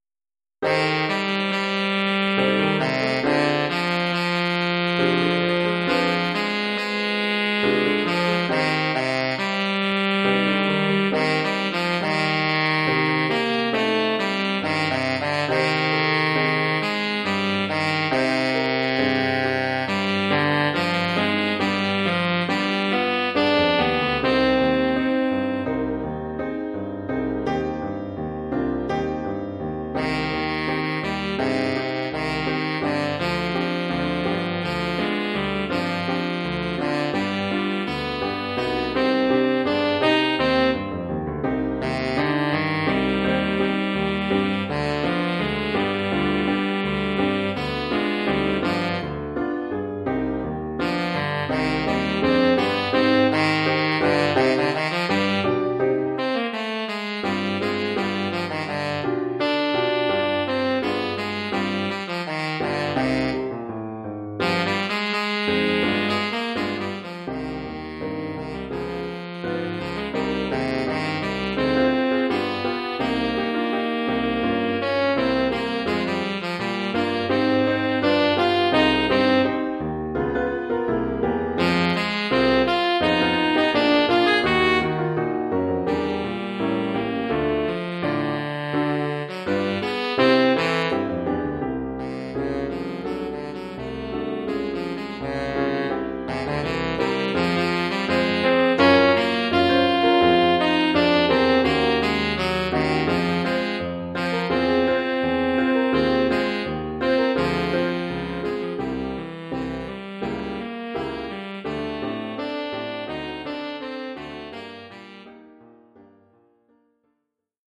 Oeuvre pour saxophone sib et piano.